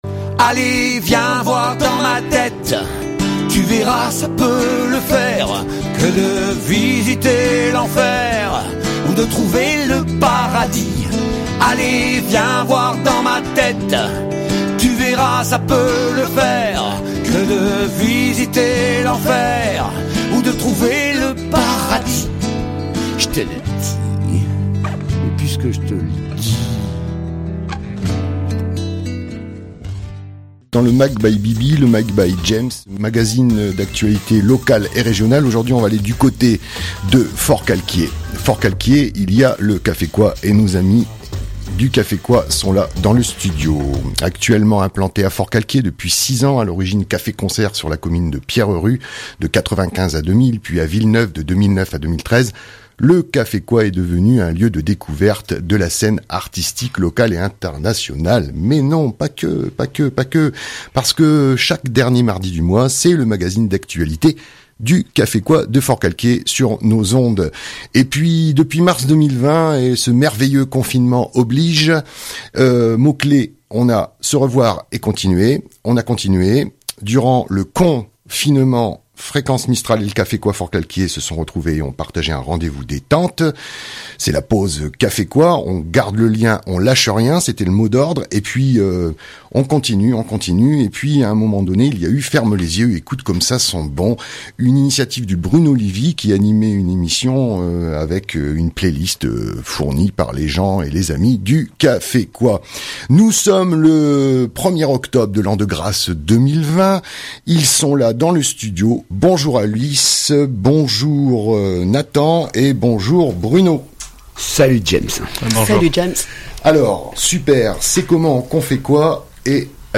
Pas du tout, nous sommes le 1er octobre 2020, et ils sont là bien présents dans le studio.